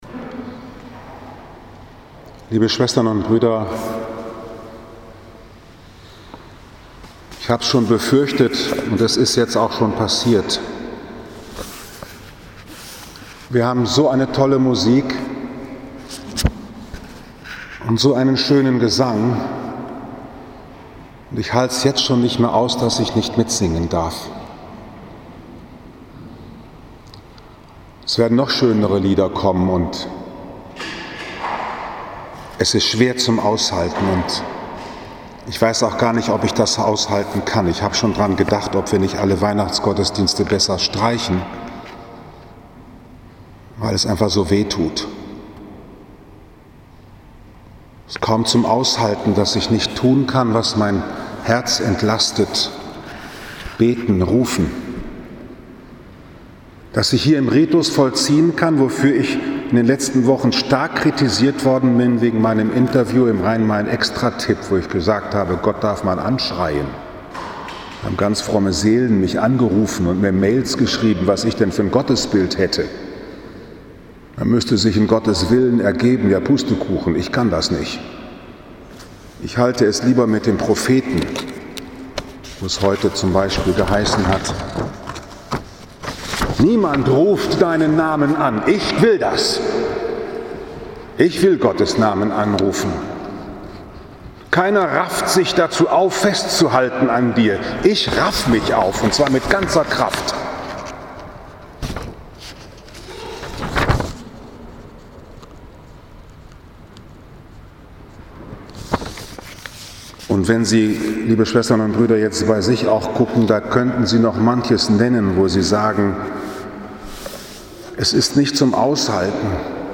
Dem Glaubenden wird alles zum Zeichen ~ Bruder Paulus´ Kapuzinerpredigt Podcast
- Wie ich zu einem neuen Verständnis des Tagesevangeliums kam 29. November 2020, Liebfrauenkirche Frankfurt am Main, 1. Advent Lesejahr B